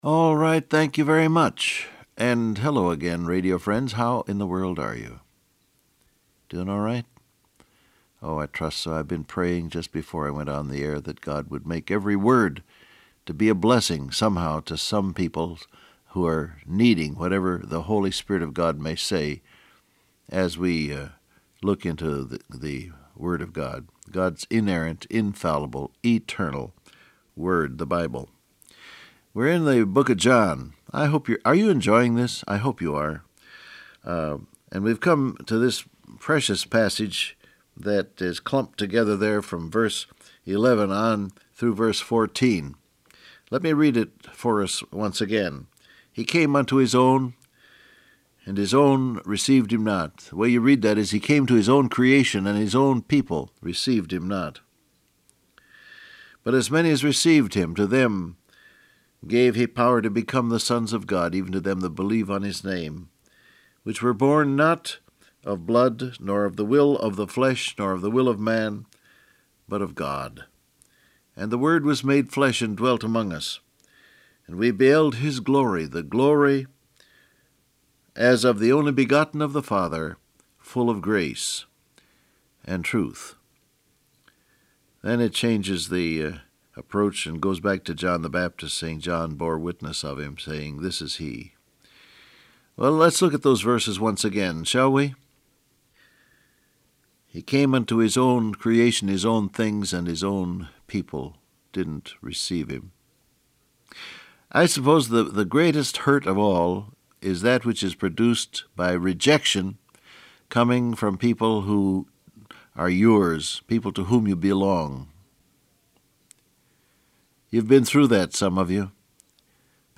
Download Audio Print Broadcast #6801 Scripture: John 1:11-14 Topics: Feelings , Rejection , Made Flesh Transcript Facebook Twitter WhatsApp Alright, thank you very much.